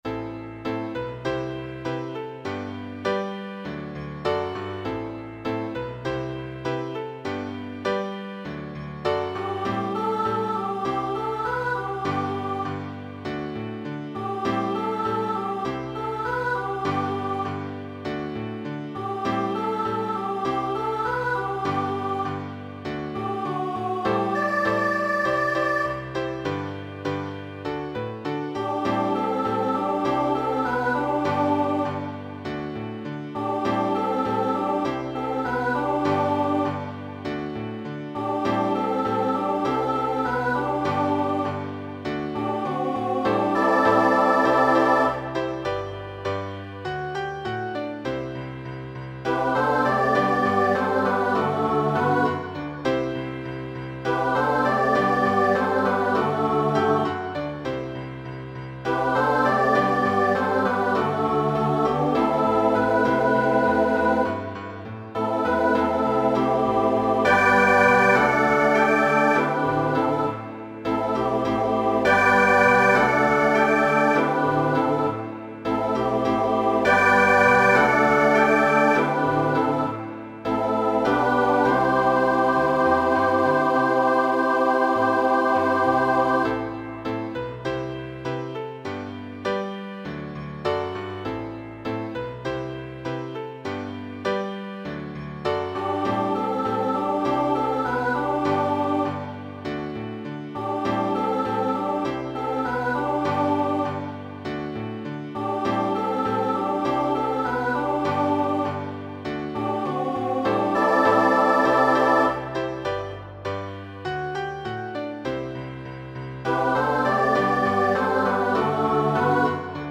Voicing SATB Instrumental combo Genre Country
Ballad